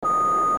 East-to-West Internet Opera
Live On-Line Low-Tech Internet Opera